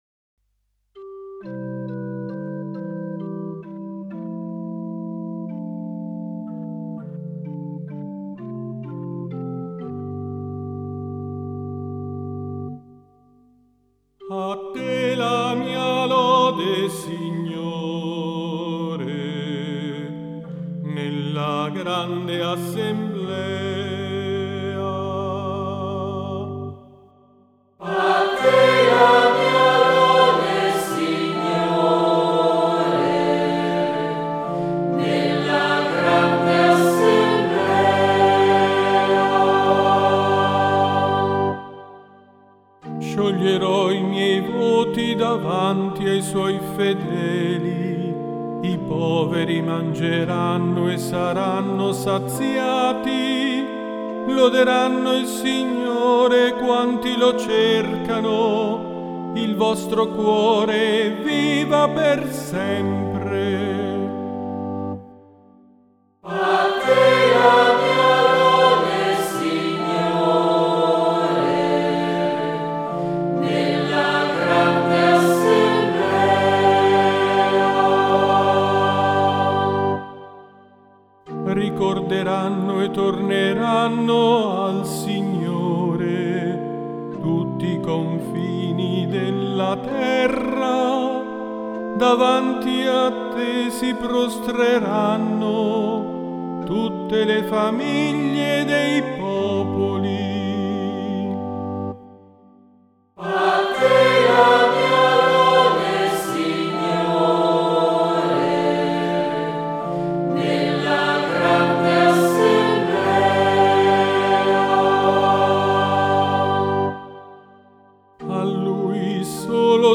Salmo responsoriale